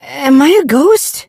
gus_die_vo_01.ogg